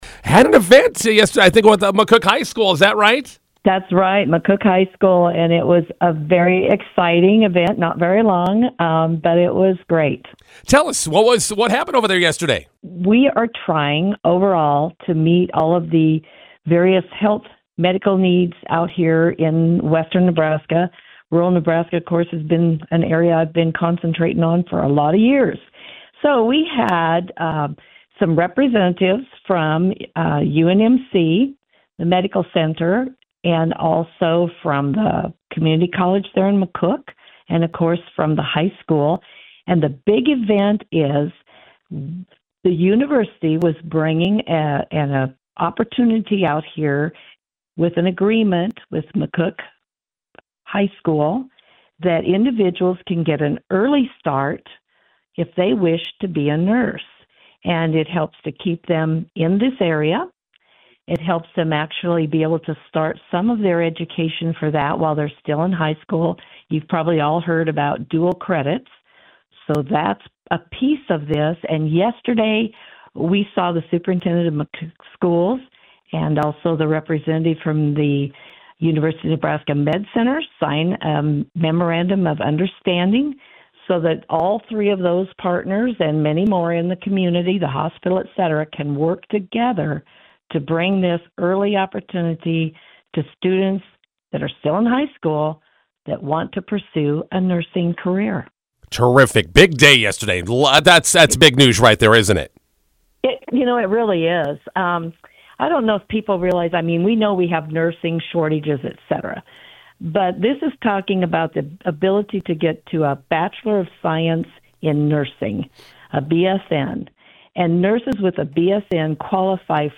INTERVIEW: McCook Public Schools and Nebraska Medical Center meet this week. More with University of Nebraska Regent Kathy Wilmot.